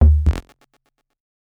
Bass Tech Alert.wav